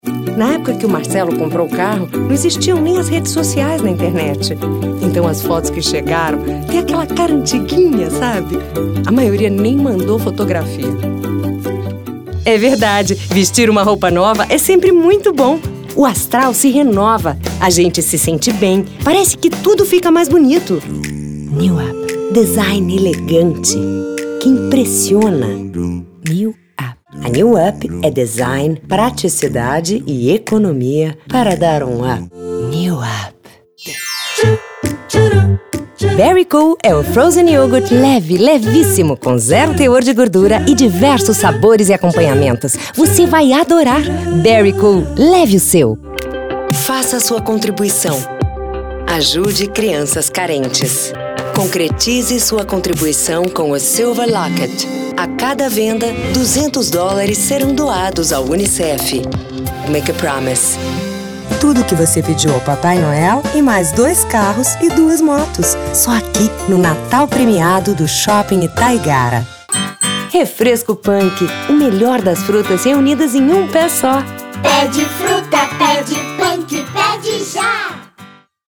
Brazilian native VO talent and actress with +20 years experience, records in BR Portuguese and English w/accents.
Sprechprobe: Werbung (Muttersprache):